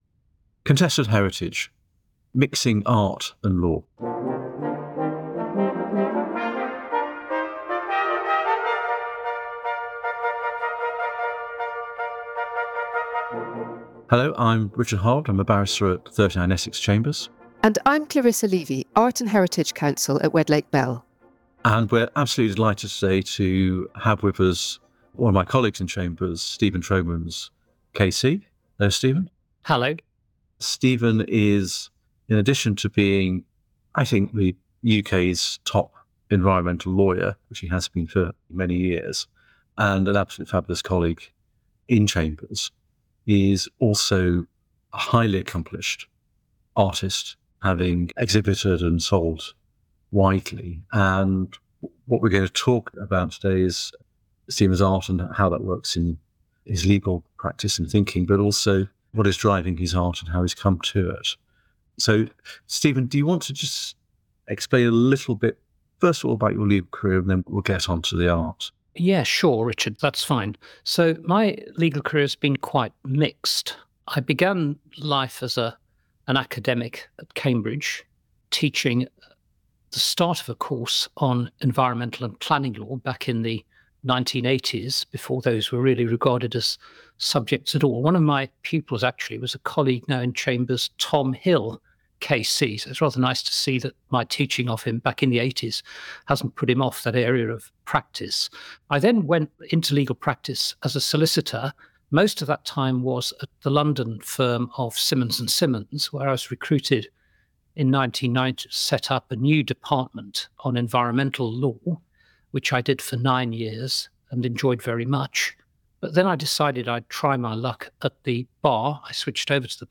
The catalogues for two of the collections discussed in the interview can be viewed here: Elemental and Golgotha